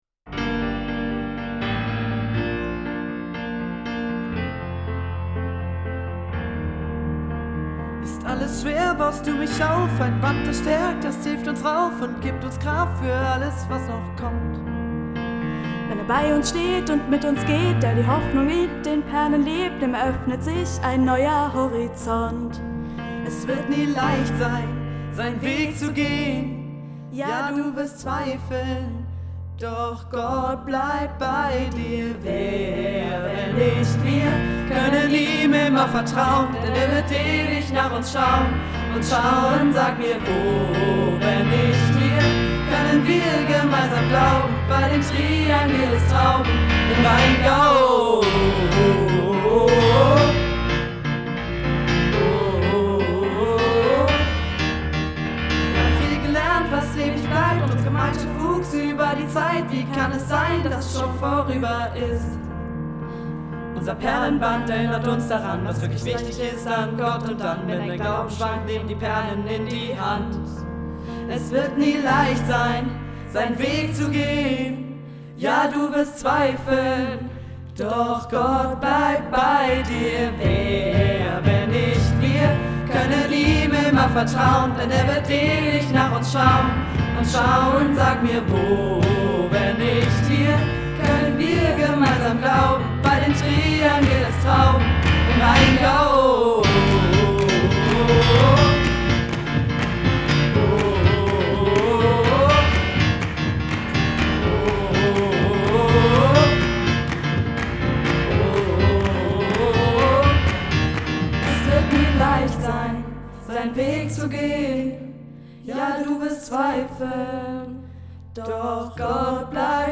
Sobald Melodie und Text stehen, wird das Ergebnis eingesungen, geschnitten und auf dem Vorstellungsgottesdienst präsentiert.